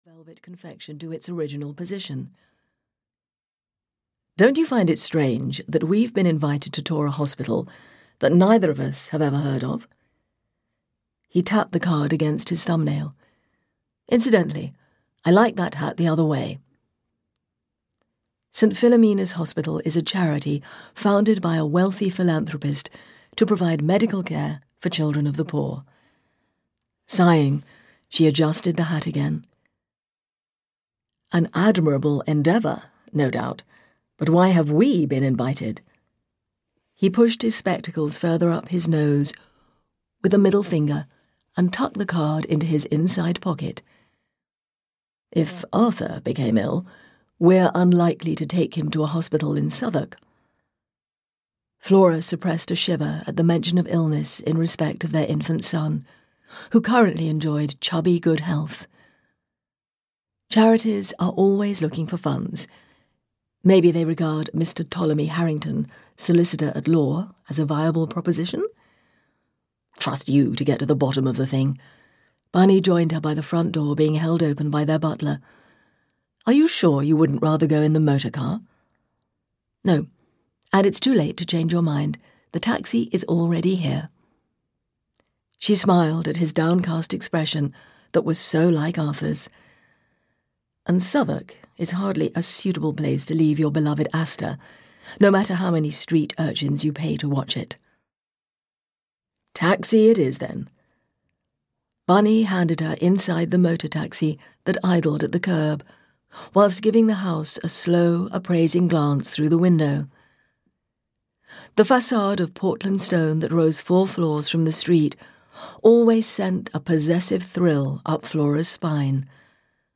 Death by the Thames (EN) audiokniha
Ukázka z knihy